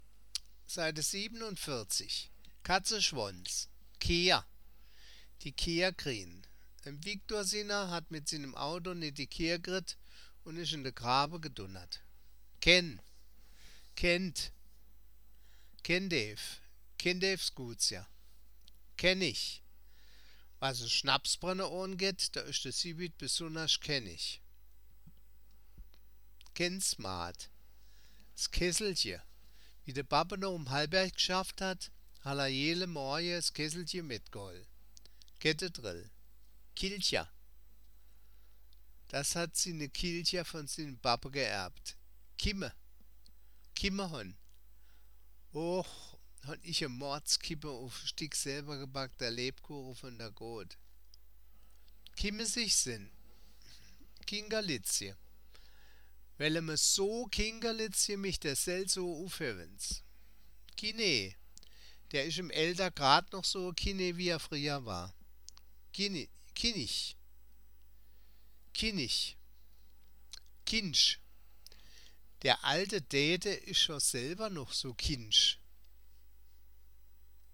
Das Wörterbuch der Ensheimer Mundart, Band I. Ensheim-Saar 1975